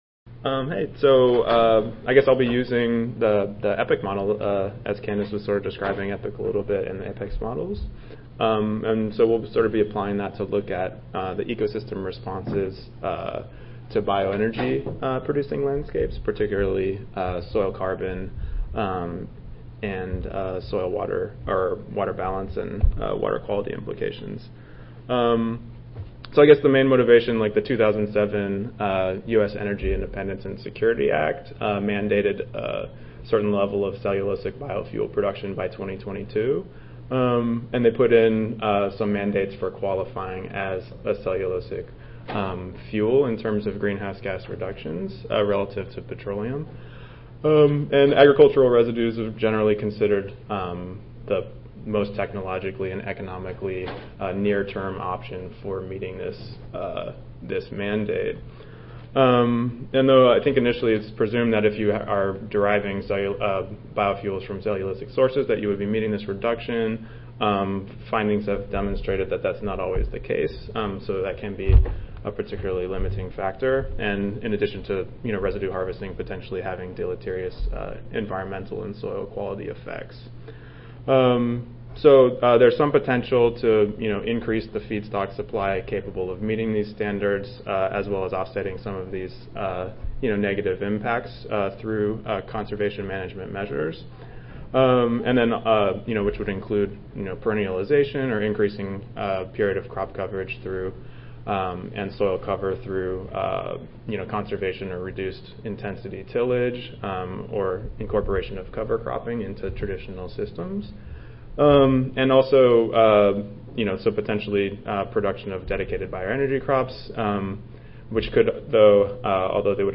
See more from this Division: ASA Section: Climatology and Modeling See more from this Session: Examples of Model Applications in Field Research Oral